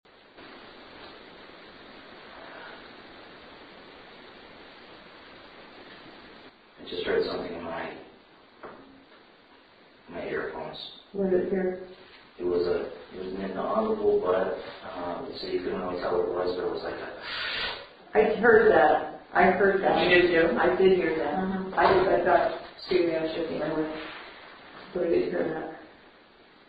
MORE HOUGHTON EVPS!
These came from Widders room on the third floor.
WiddersRm7a-BreathWEHEARD – This is a breath sound that almost sounds like “Hello”.
WiddersRm7a-BreathWEHEARD.mp3